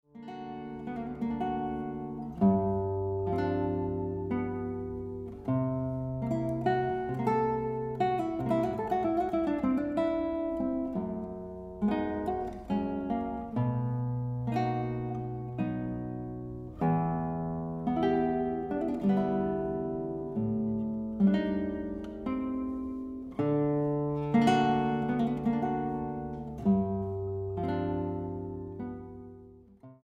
guitarra
Andante un po'mosso